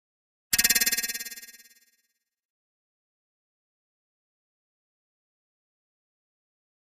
Ping | Sneak On The Lot